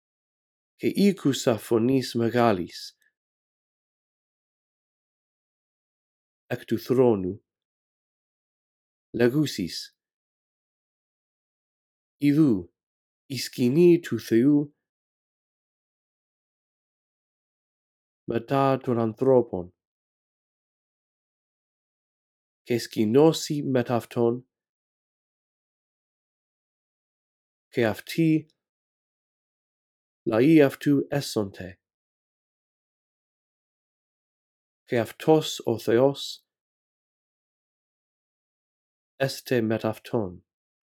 In this audio track, I read through verse 3 a phrase at a time, giving you time to repeat after me. After two run-throughs, the phrases that you are to repeat become longer.